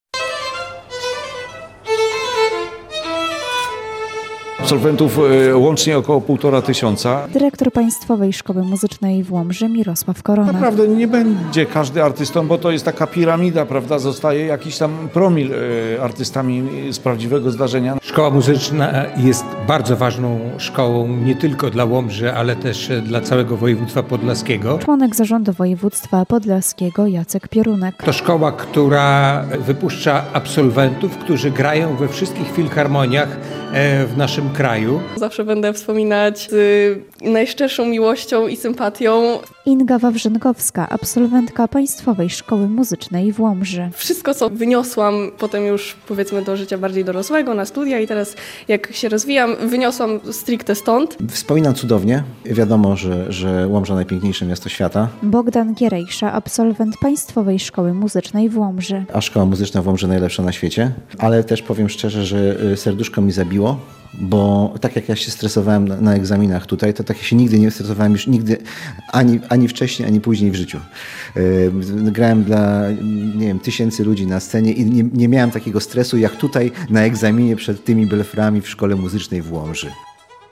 Koncert absolwentów rozpoczął uroczystości jubileuszowe 60-lecia powstania Państwowej Szkoły Muzycznej  I i II stopnia w Łomży.
Poniżej relacja z uroczystości: